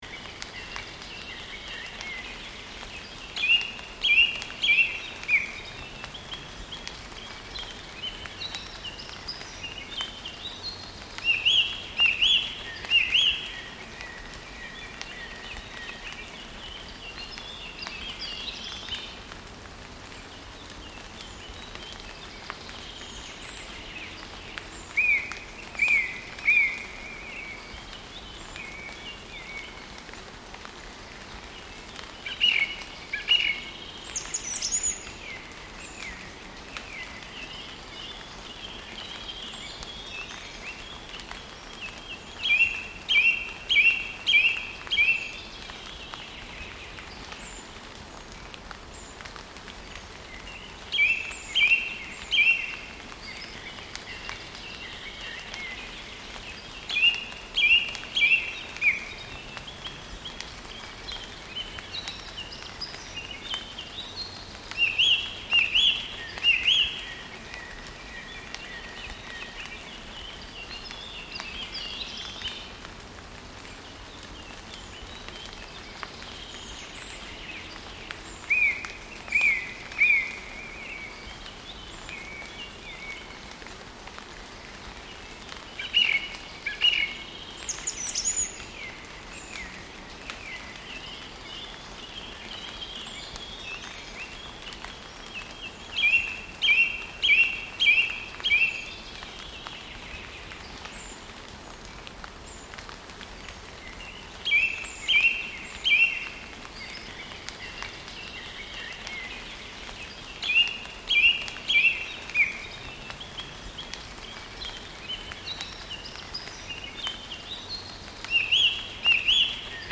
Download Free Rain Sound Effects | Gfx Sounds
Light-rain-forest-ambience-pattering-loop-2.mp3